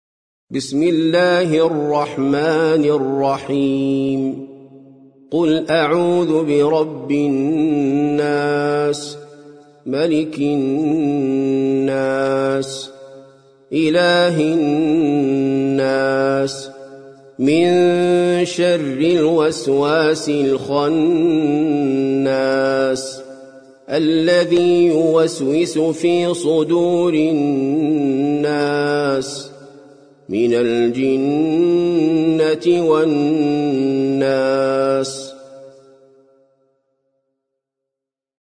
سورة الناس - المصحف المرتل (برواية حفص عن عاصم)